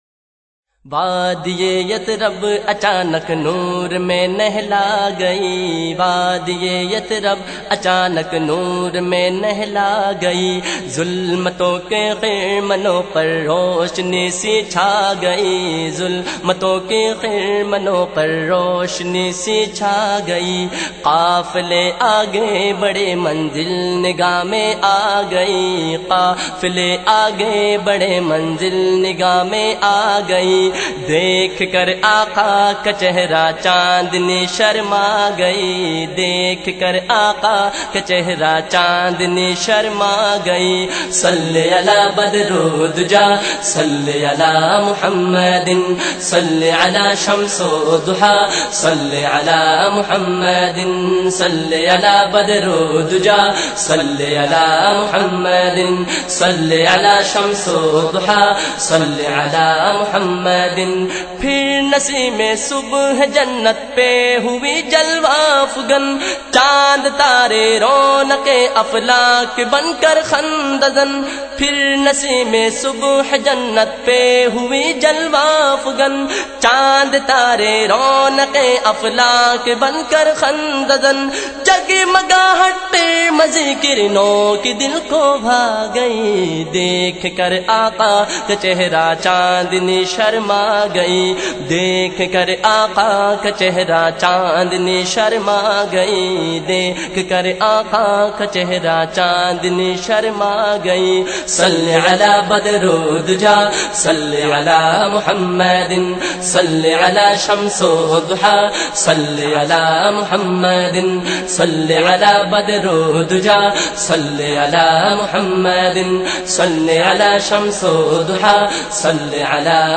His amazing accent draws in his followers.